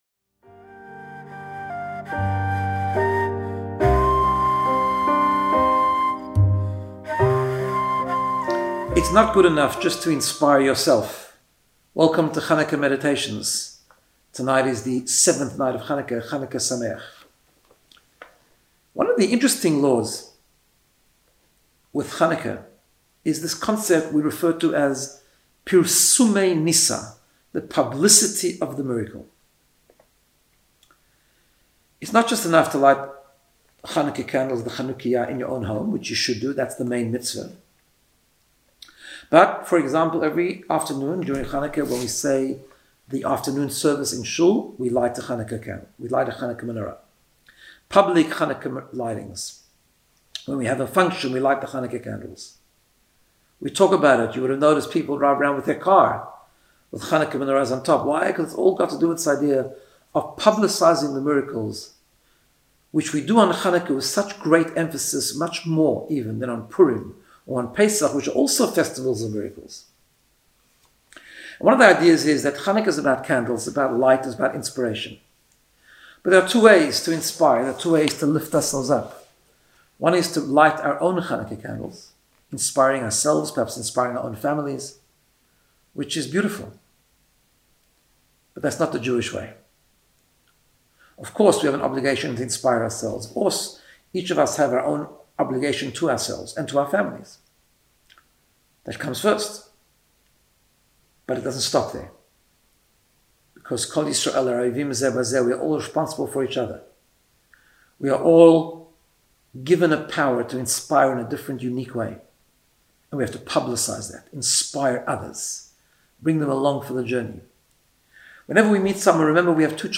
Guided Meditation to get you in the space of Personal Growth and Wellbeing.